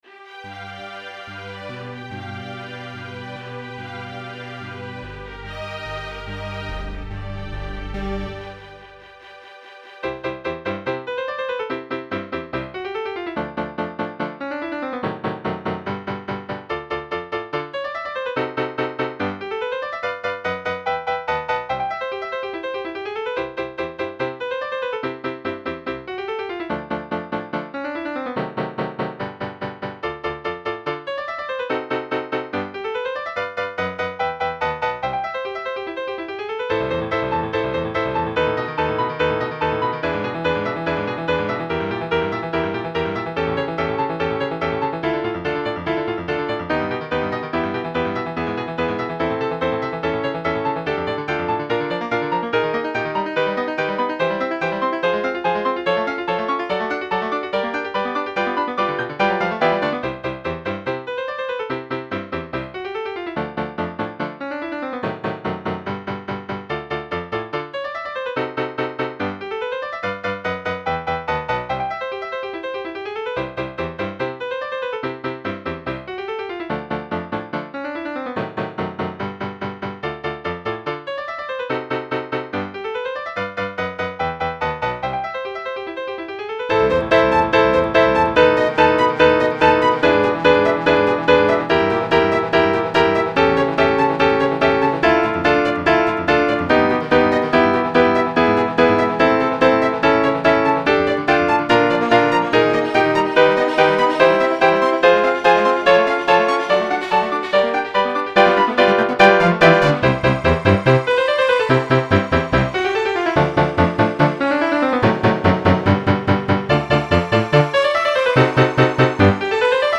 Excursion in C for Multiple Keyboards